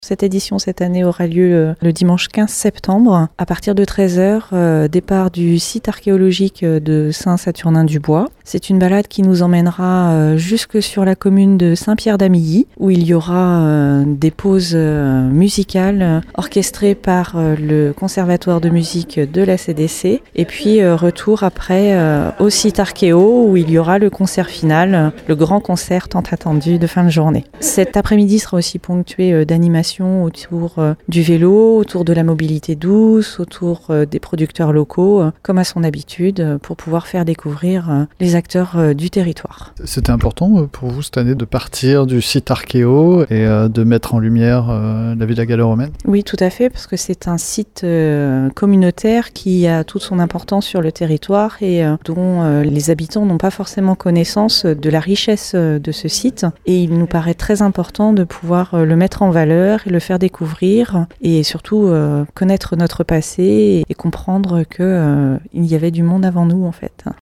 Barbara Gauthier est conseillère déléguée au tourisme à la Communauté de communes Aunis Sud.